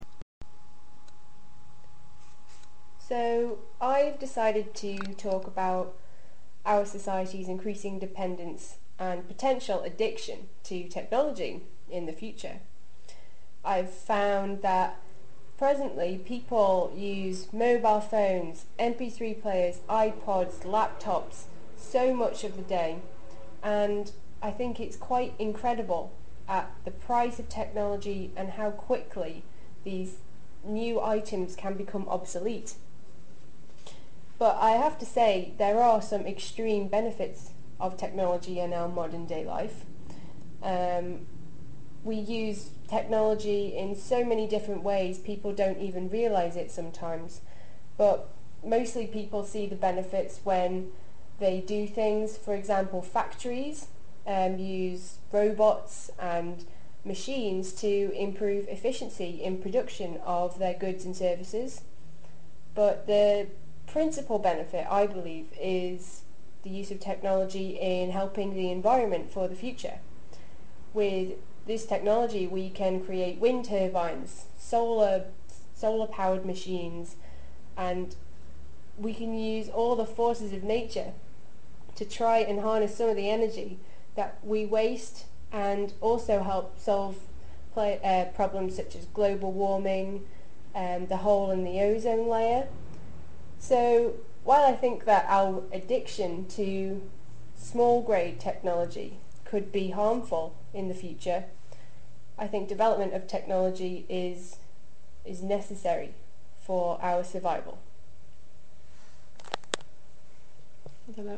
Accent
Ile de Man